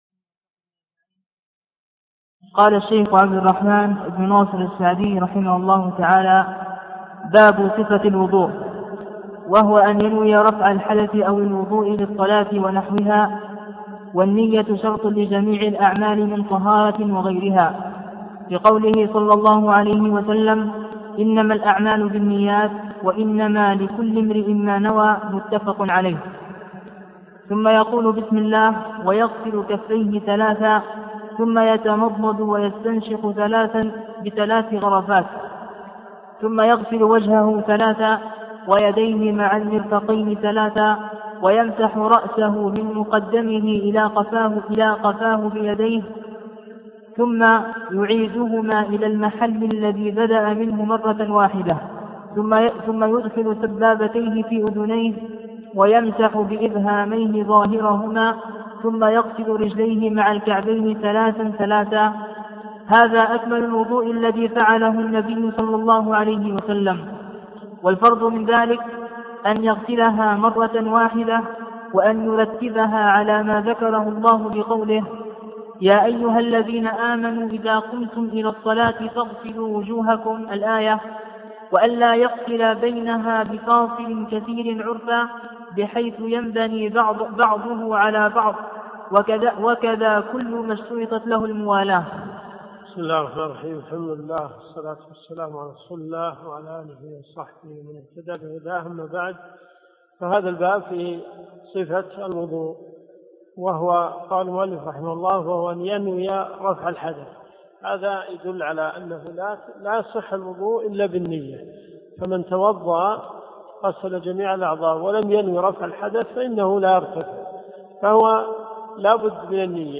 دروس صوتيه
منهج السالكين . كتاب الطهارة . من ص 13 باب صفة الوضوء -إلى- ص 17 قوله الخفيفة والكثيفة . المدينة المنورة . جامع البلوي